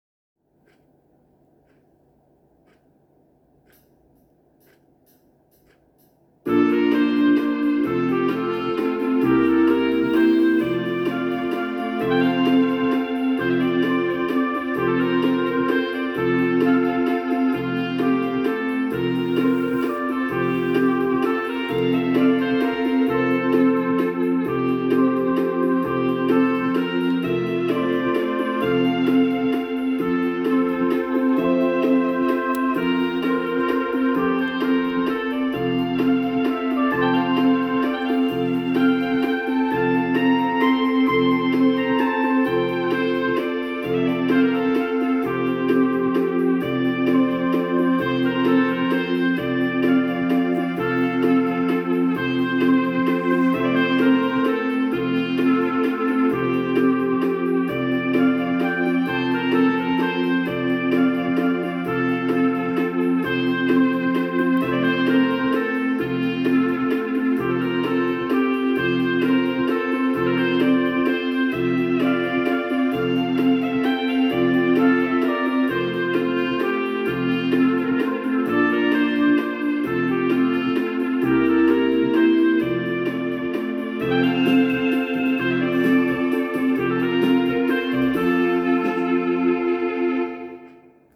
Geläut der Domglocken zum Download.
Domglockenwalzer.m4a